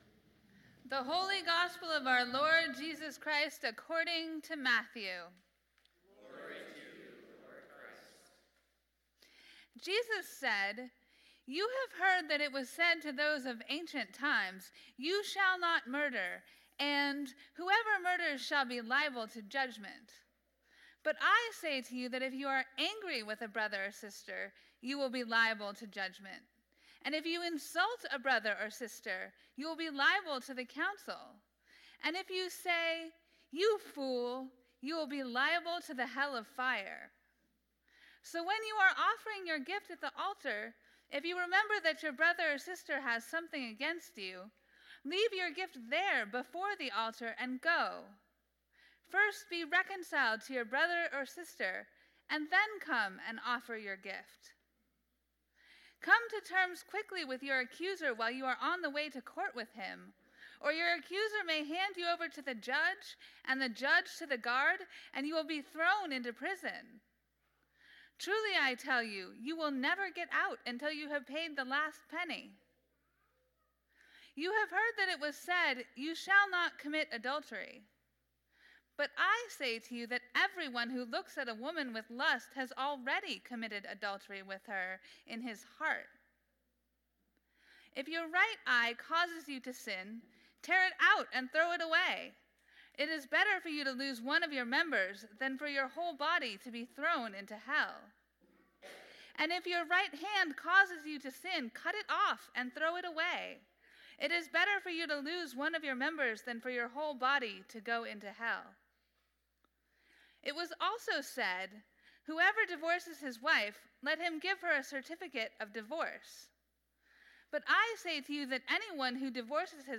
Gospel Reading: Matthew 5:21-37